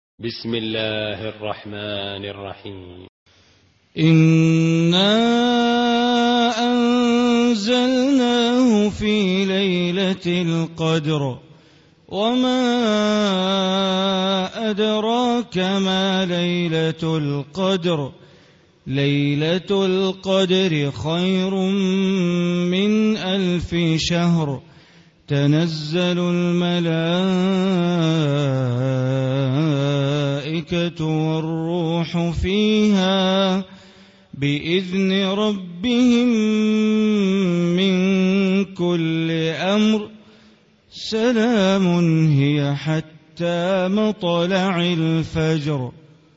Surah Qadr Recitation by Sheikh Bandar Baleela
Surah Qadr, listen online mp3 tilawat / recitation in Arabic, recited by Imam e Kaaba Sheikh Bandar Baleela.